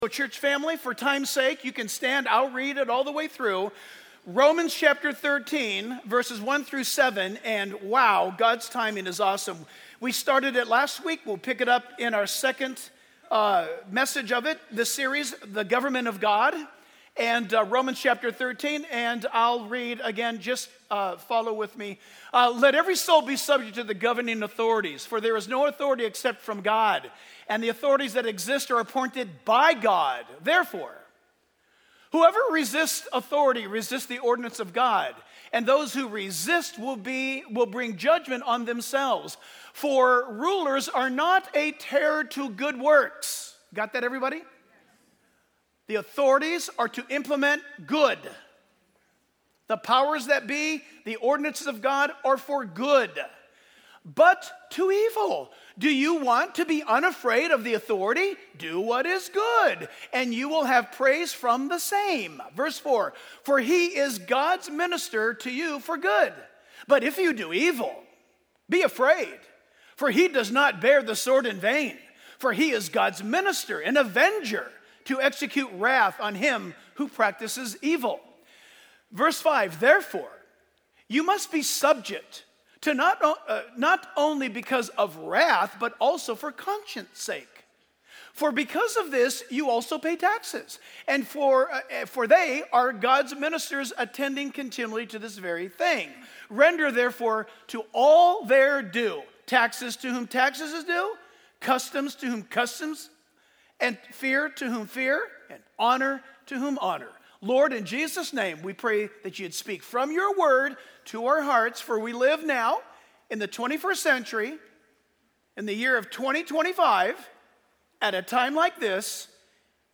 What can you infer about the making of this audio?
The Bible study given at Calvary Chapel Corvallis on Sunday, May 4, 2025.